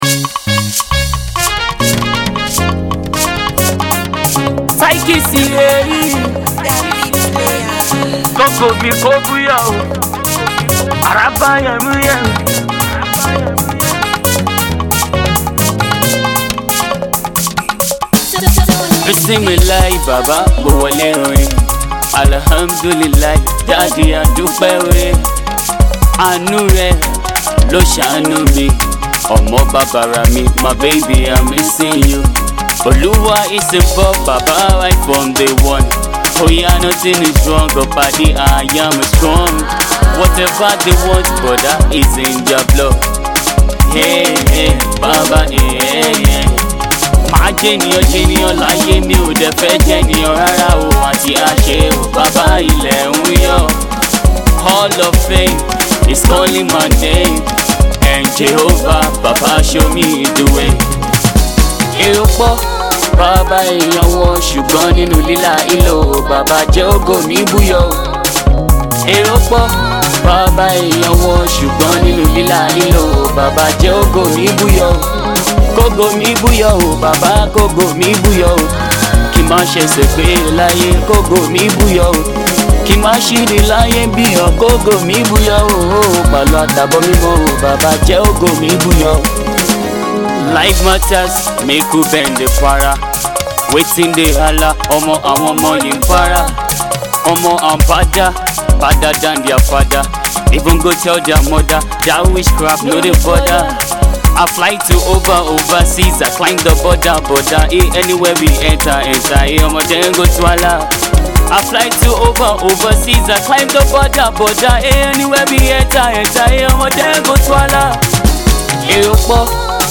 Indigenous flavour